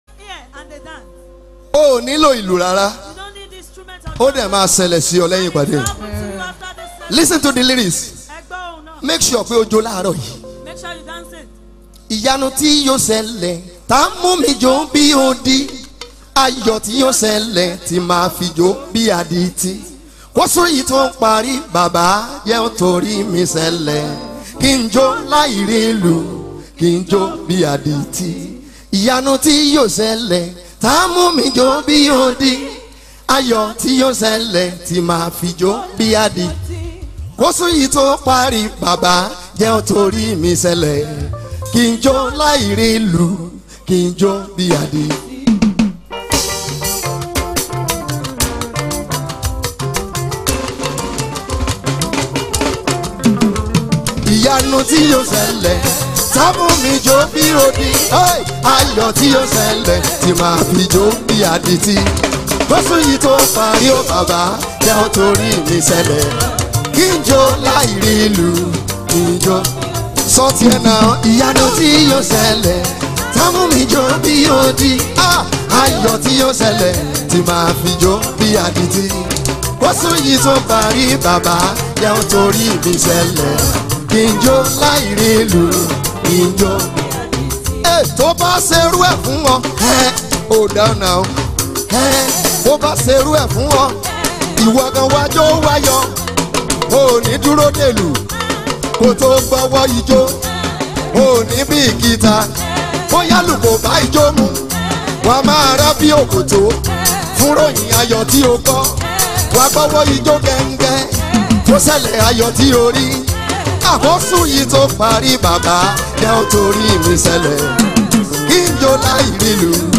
gospel
This song is simple but spirit-filled song…